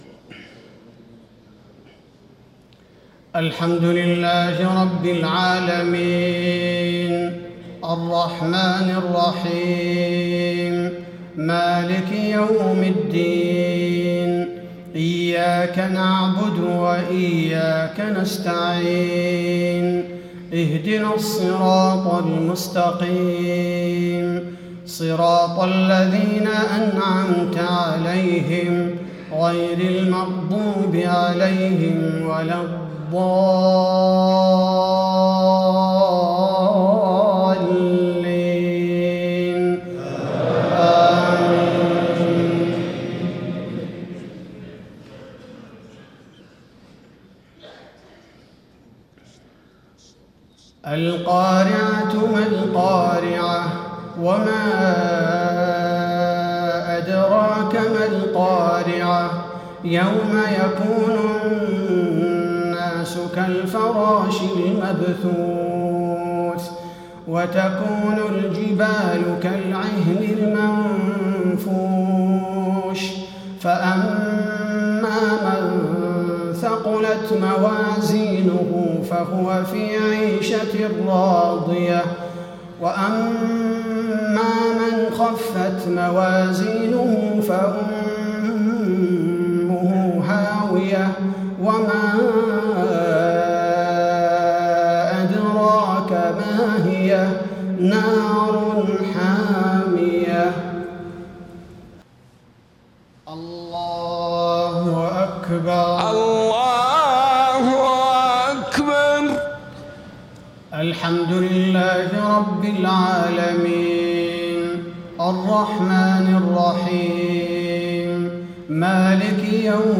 صلاة الجمعة 1 - 5 - 1436هـ سورتي القارعة و الهمزة > 1436 🕌 > الفروض - تلاوات الحرمين